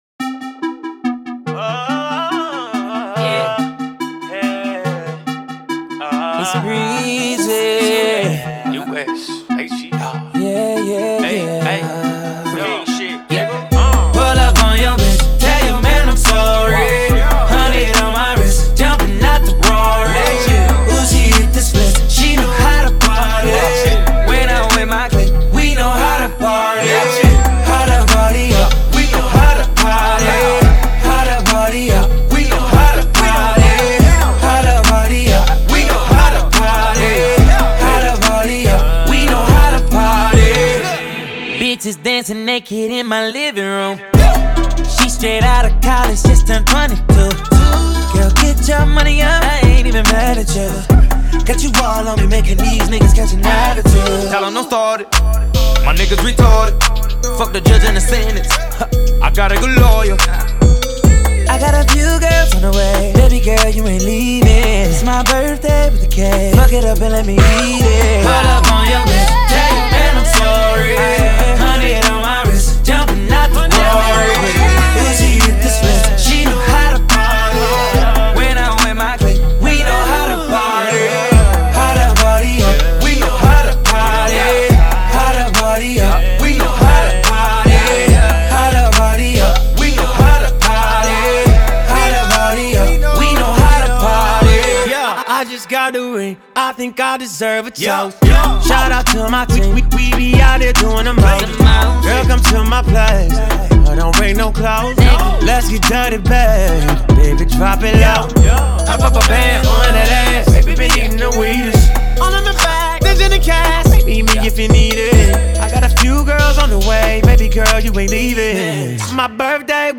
HipHop/Rnb